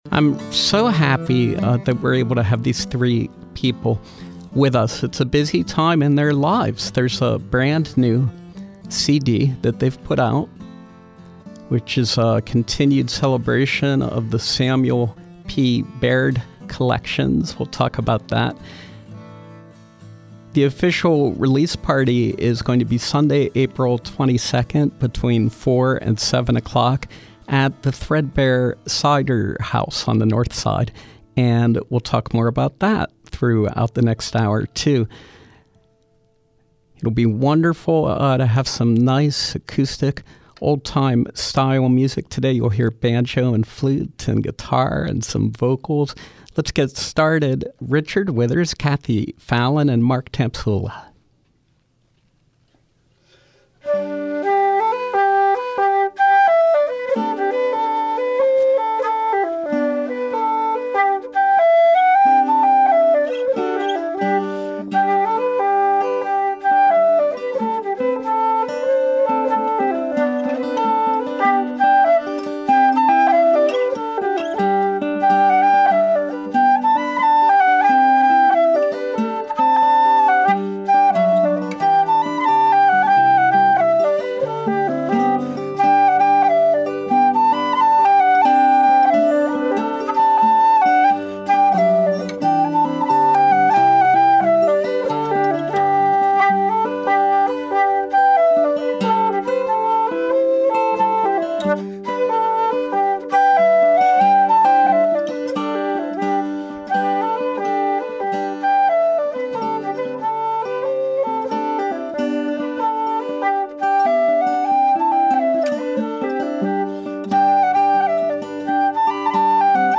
old-time music